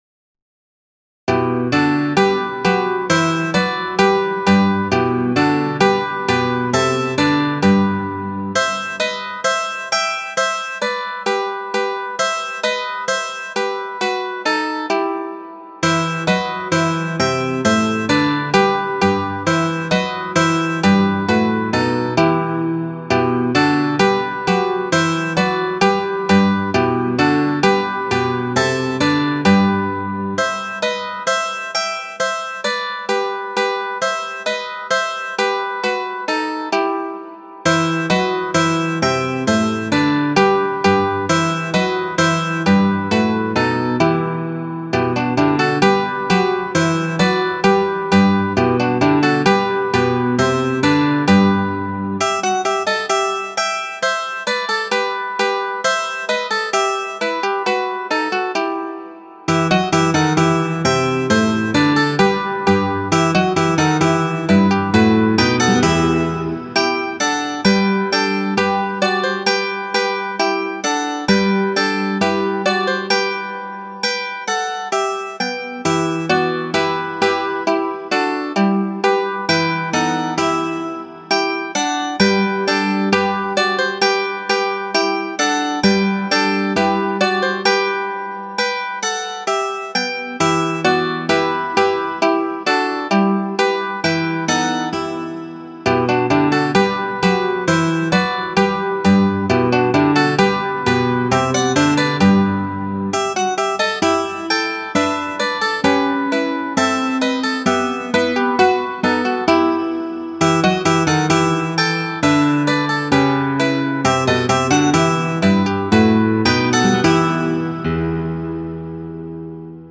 Concertino für 3 Mandolinen (+ Gitarre ad lib.)